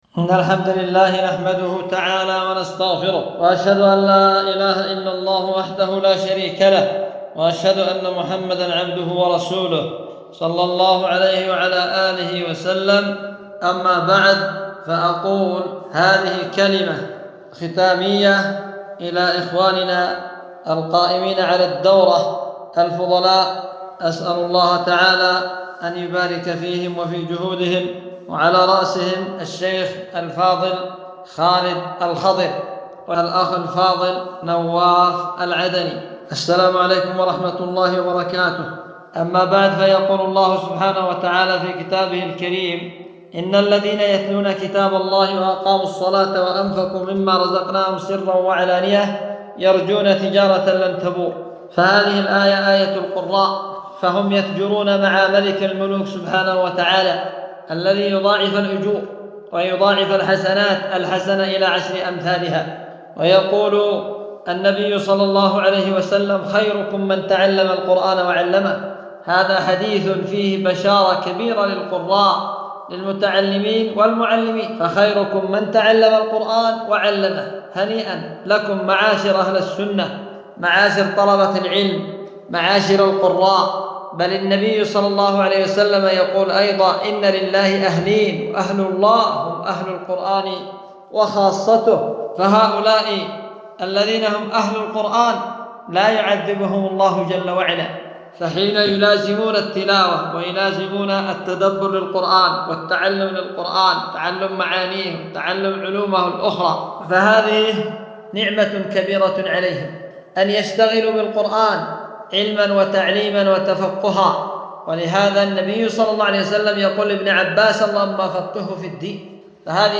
محاضرة في ختام الدورة المقامة في مسجد الكون بعدن